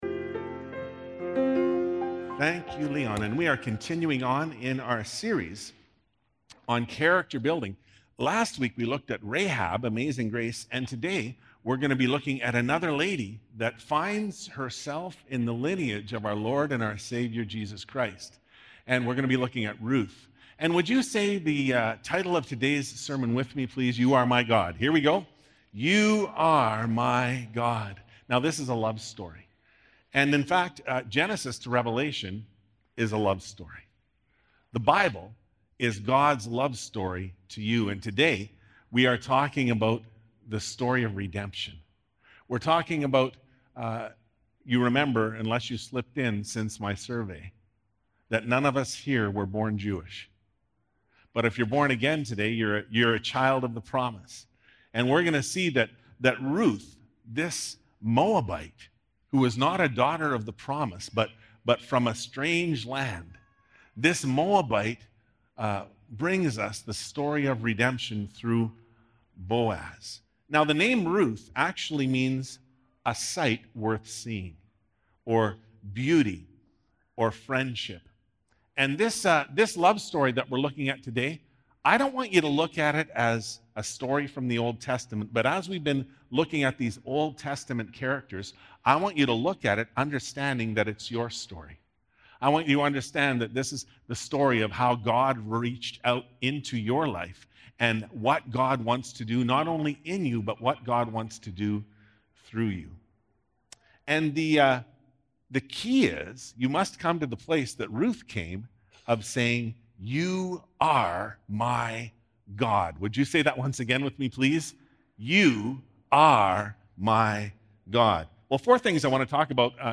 Sermons | Southside Pentecostal Assembly